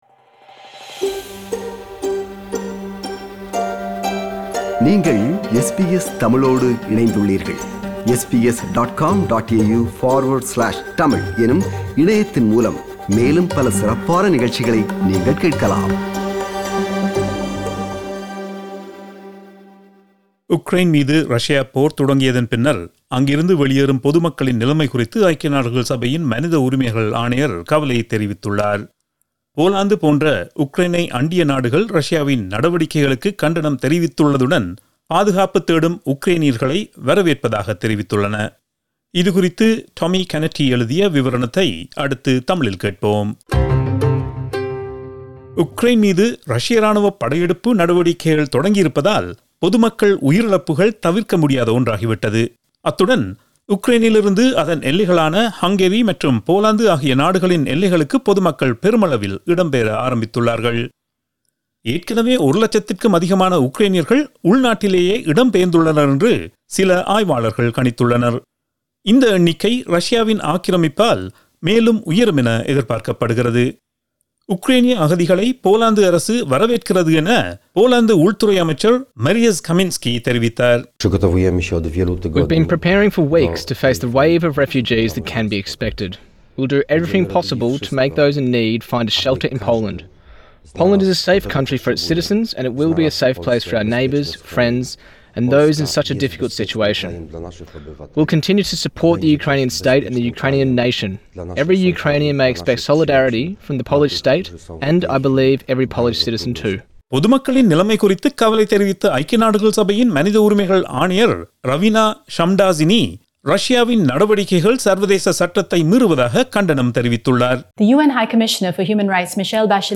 SBS Tamil